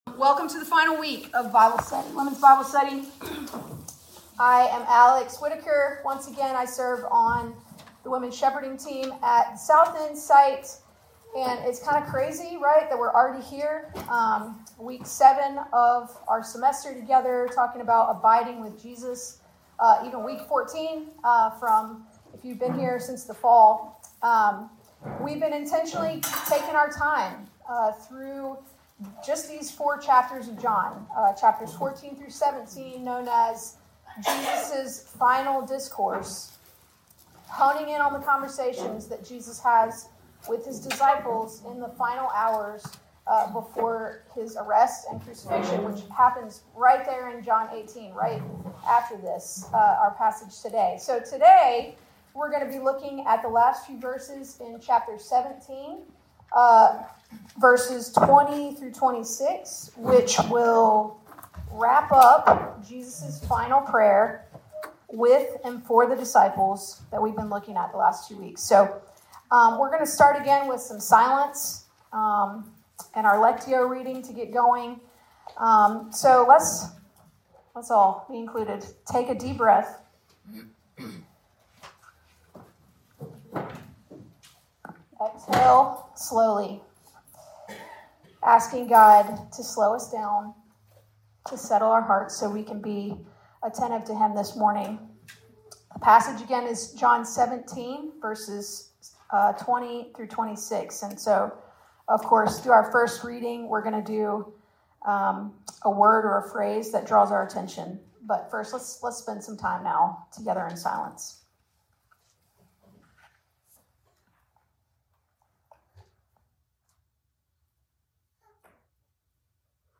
From Location: "Women's Bible Study"